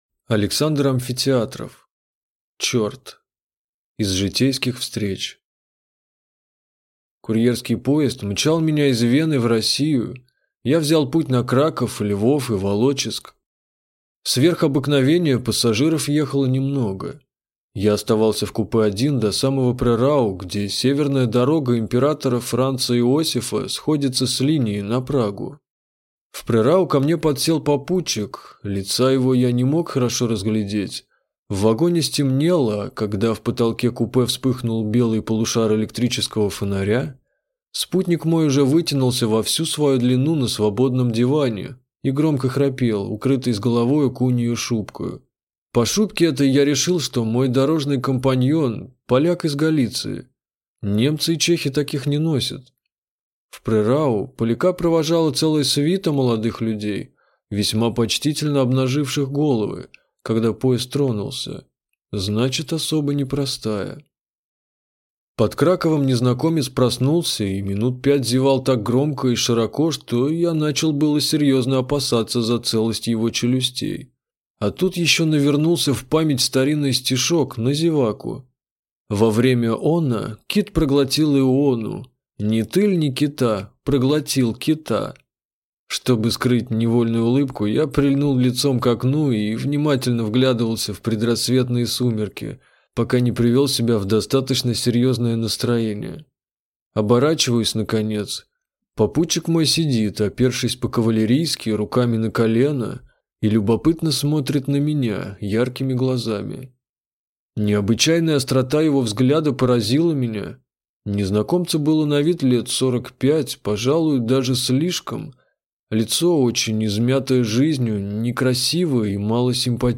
Аудиокнига Черт | Библиотека аудиокниг